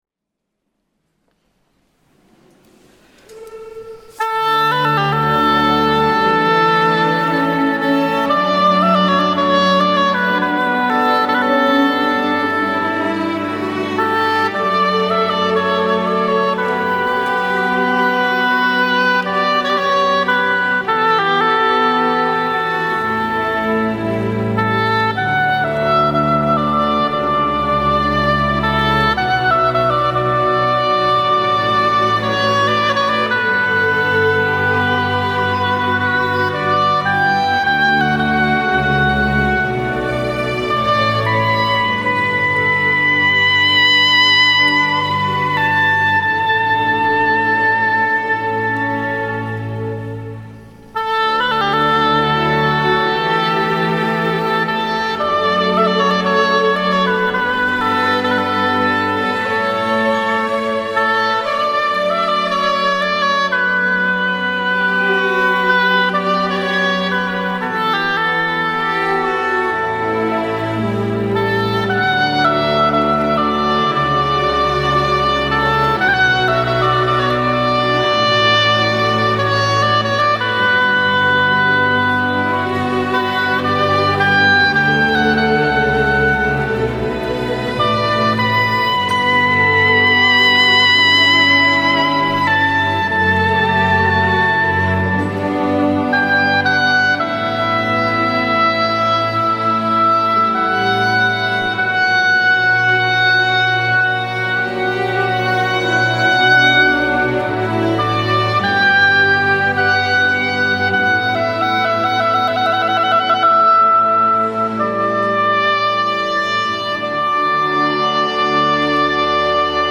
Sabato 05 maggio 2012 la corale ha eseguito la prima edizione del Concerto di S. Eurosia, in collaborazione con l'orchestra "L'Incanto Armonico" di Pisogne (BS).